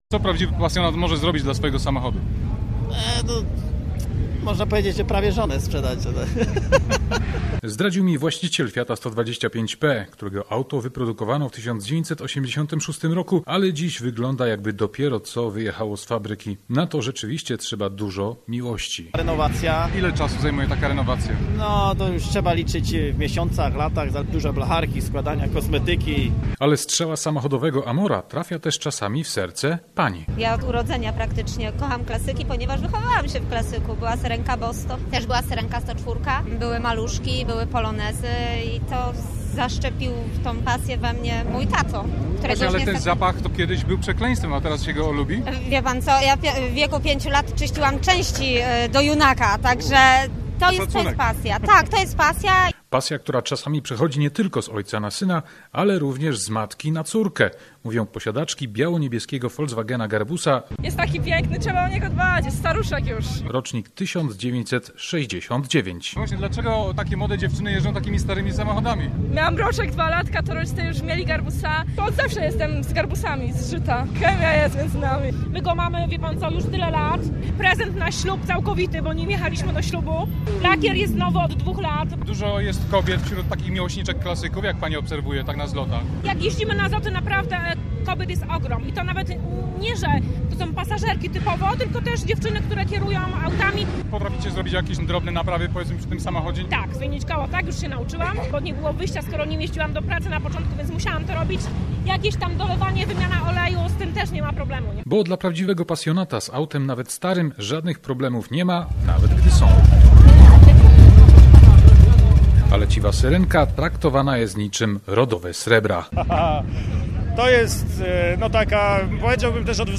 W niedzielę, 15 października, w Gnieźnie wczoraj odbył się ostatni letni zlot aut klasycznych. Za co kocha się klasyki?